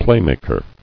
[play·mak·er]